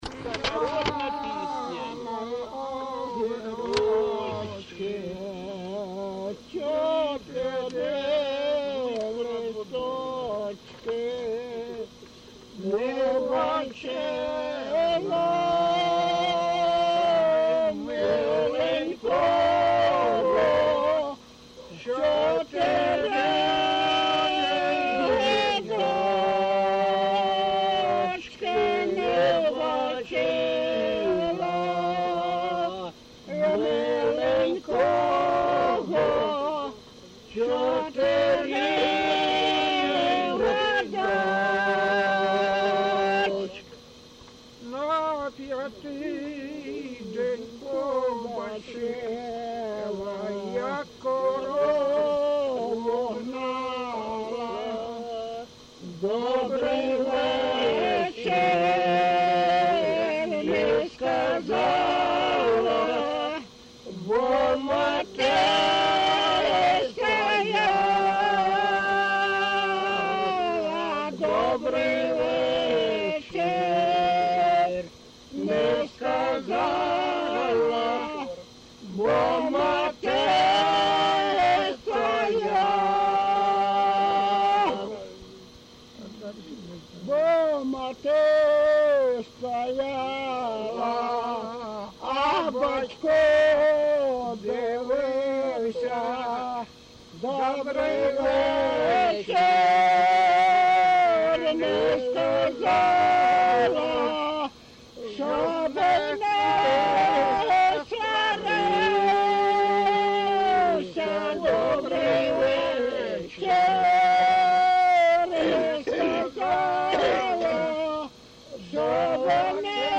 ЖанрПісні з особистого та родинного життя
Місце записус. Клинове, Артемівський (Бахмутський) район, Донецька обл., Україна, Слобожанщина
(співає також невідомий чоловік)